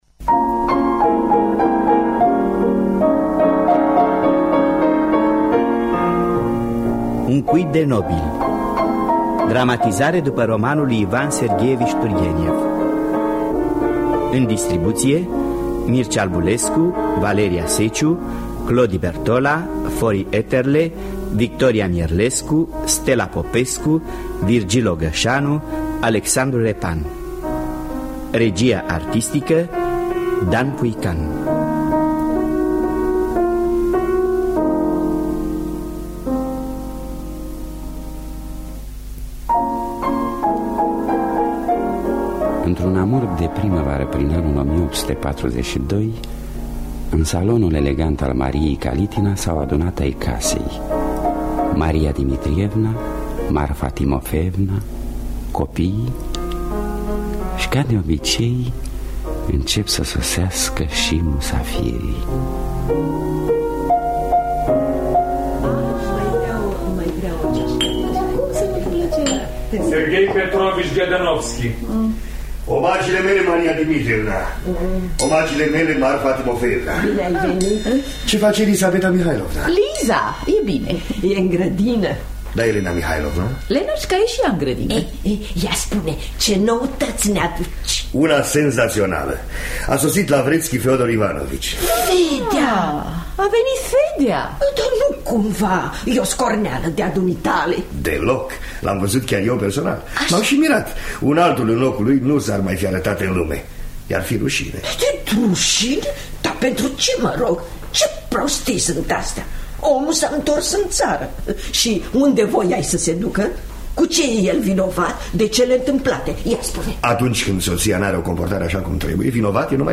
Ivan Sergheevici Turgheniev – Un Cuib De Nobili (1976) – Teatru Radiofonic Online
Înregistrare din anul 1976.